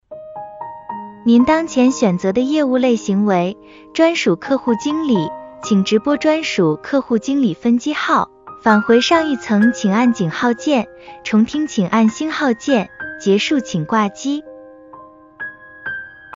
IVR音频录制试听案例：